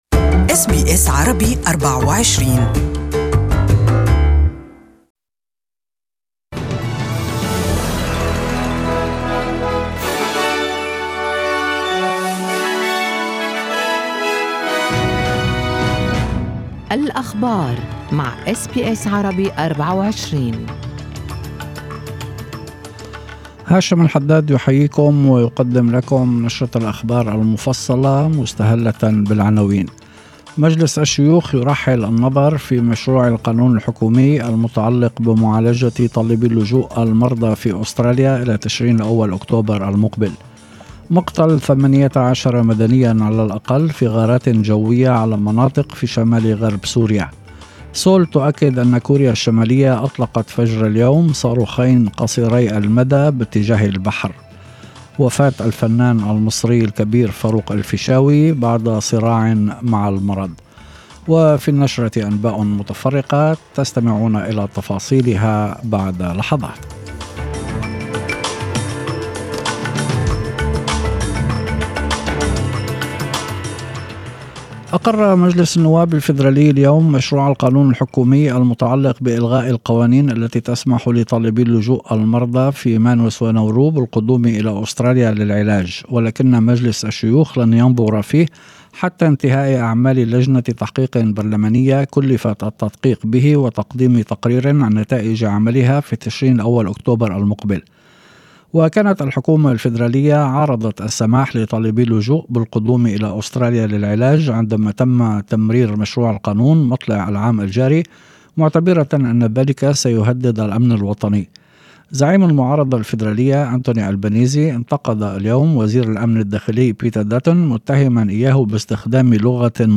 Evening News:Government's bid to repeal Medevac legislation passes first hurdle